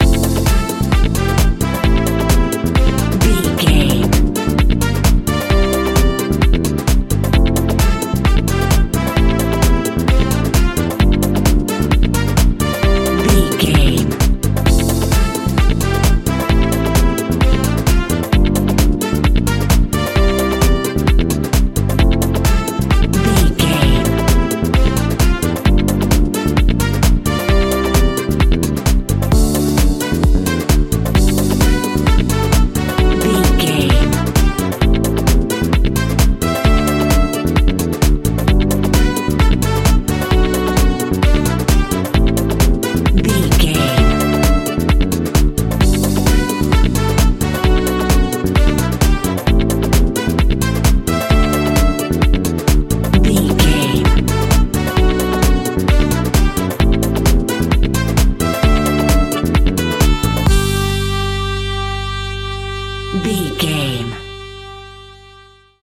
Ionian/Major
groovy
uplifting
energetic
bass guitar
brass
saxophone
drums
electric piano
electric guitar
synth
upbeat
instrumentals